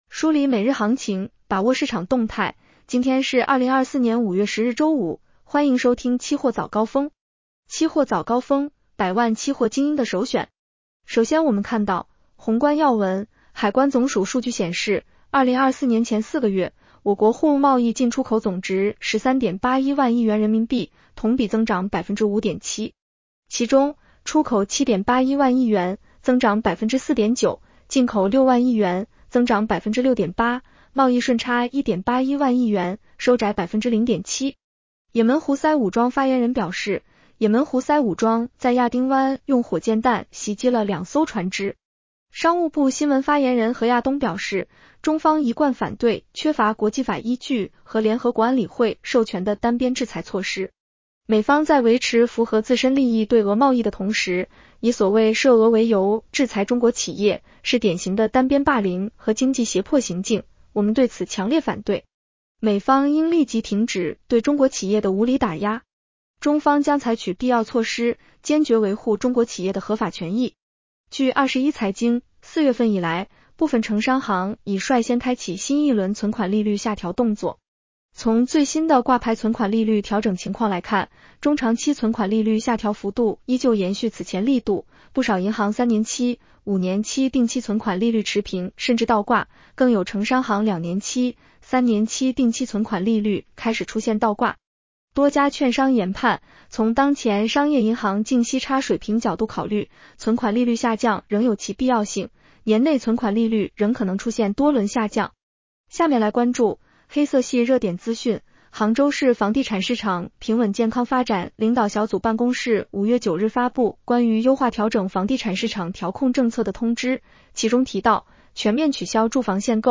期货早高峰-音频版 女声普通话版 下载mp3 宏观要闻 1.海关总署数据显示，2024年前4个月，我国货物贸易进出口总值13.81万亿元人民币， 同比增长5.7% 。